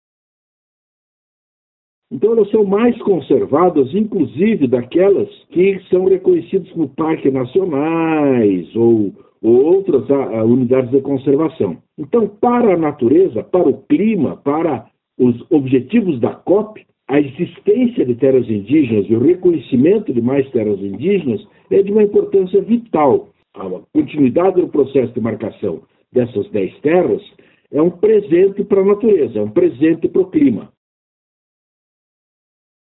SONORA-DEMARCA-PARANA-01-BO.mp3